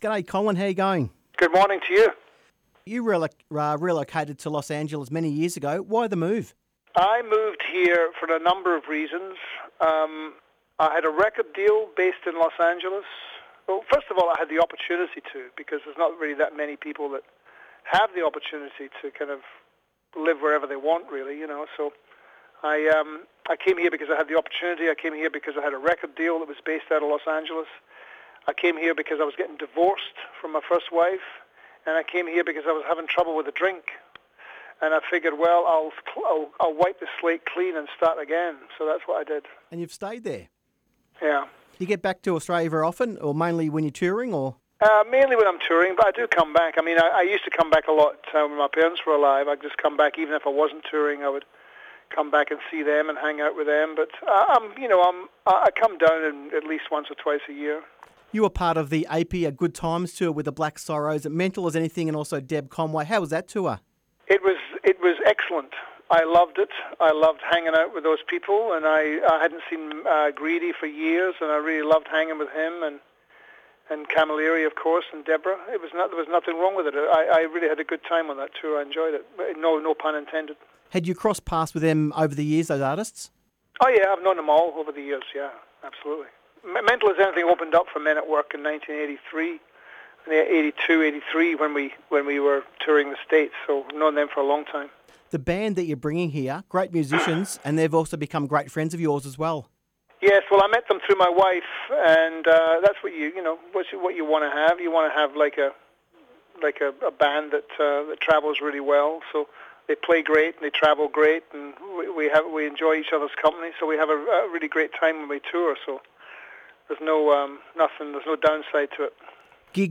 Colin Hay interview